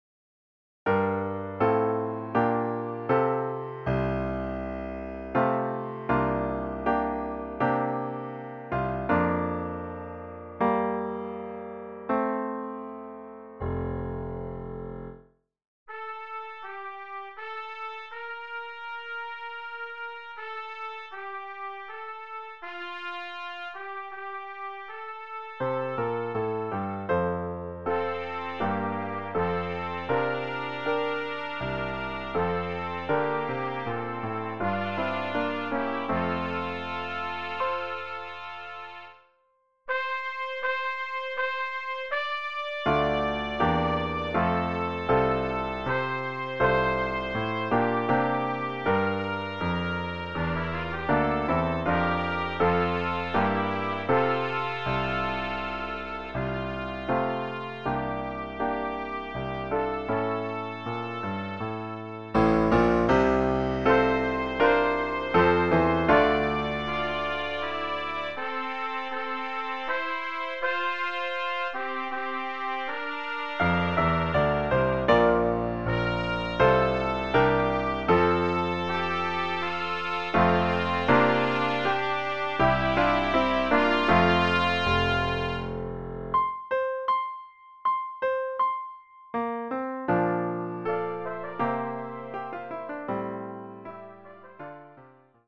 Trumpet Duet